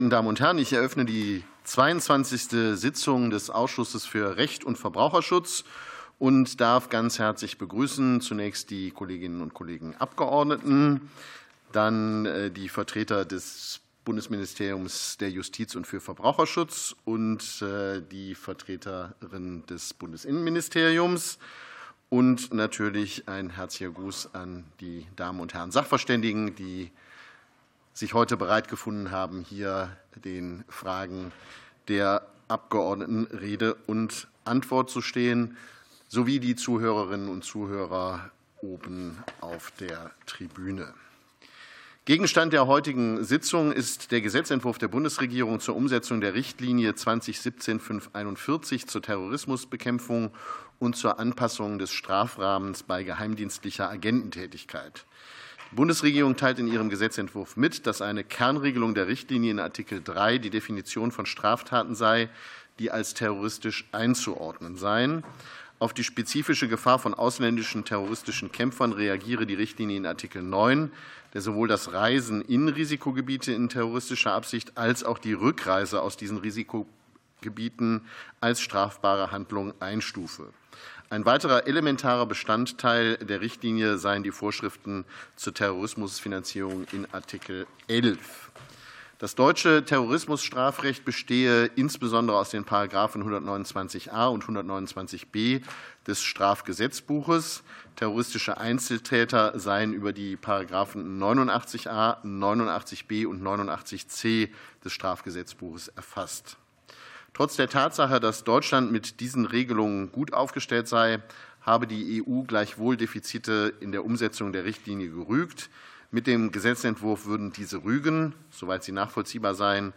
Anhörung des Ausschusses für Recht und Verbraucherschutz